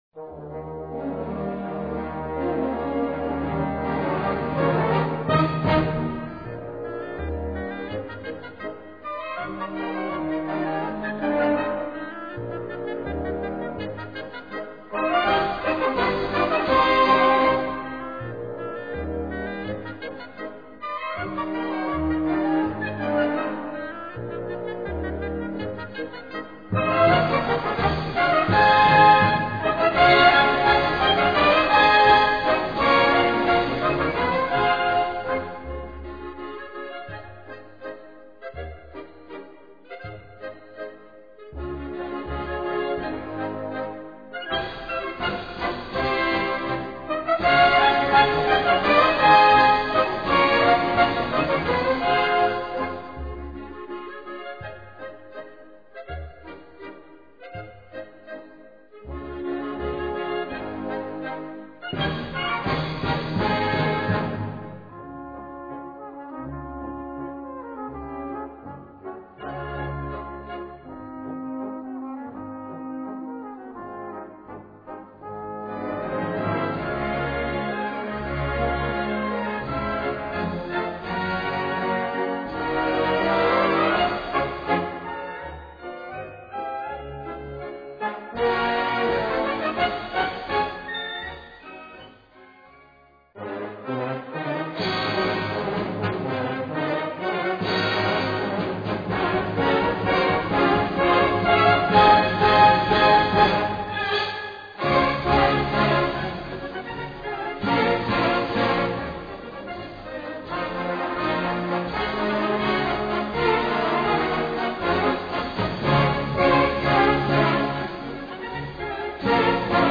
Categoría Banda sinfónica/brass band
Subcategoría Polca
Instrumentación/orquestación Ha (banda de música)